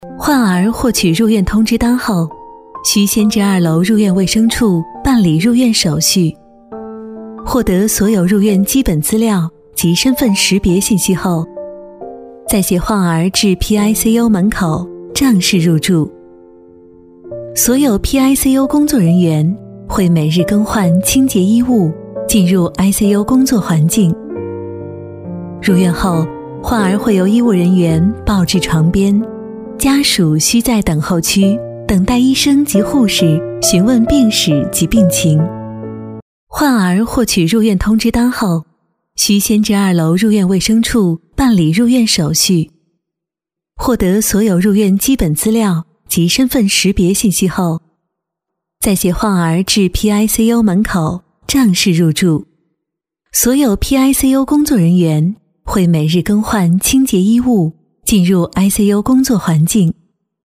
专题女15号 （儿童医学中
甜美亲切 企业专题
质感女音，风格甜美、亲切温情、幽远韵味、大气稳重都可把握，擅长宣传片，科技感等。